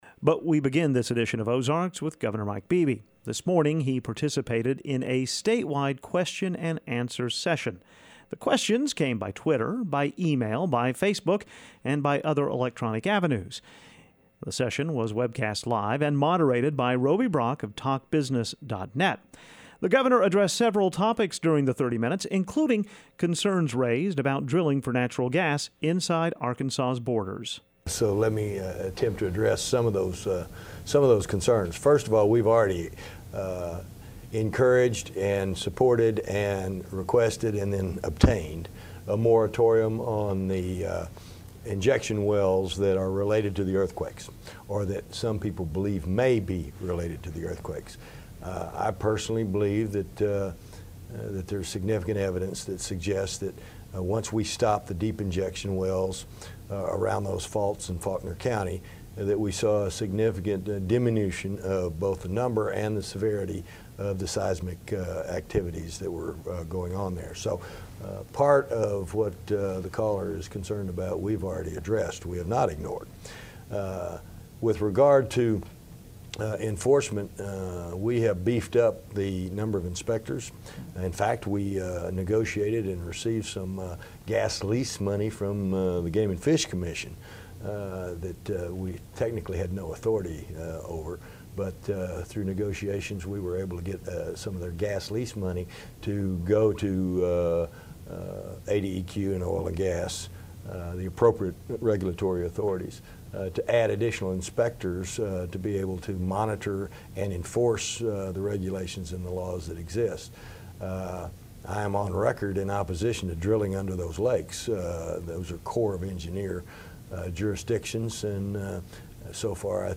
moderated a statewide Q & A session with Governor Mike Beebe.